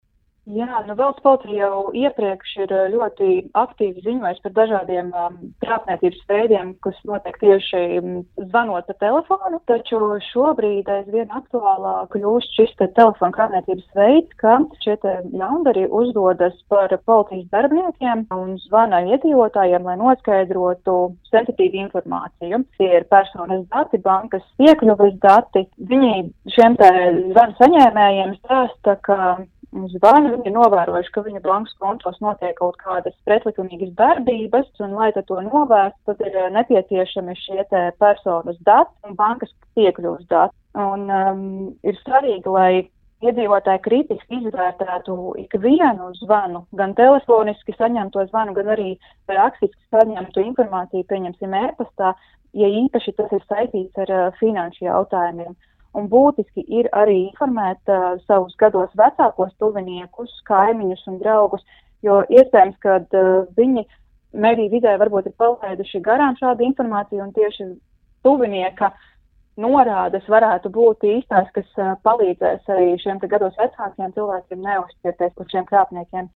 RADIO SKONTO Ziņās par krāpniekiem, kuri uzdodas par policijas darbiniekiem.